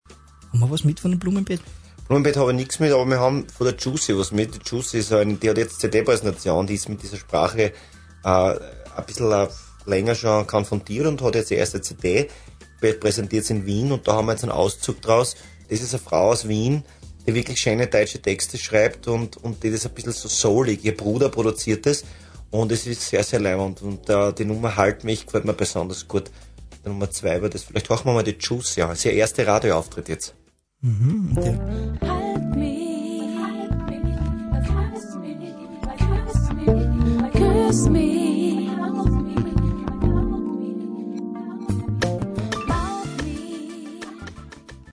Zwar wurde ihr Name noch nicht richtig ausgesprochen [ju:tsi], aber alles extrem sympathisch!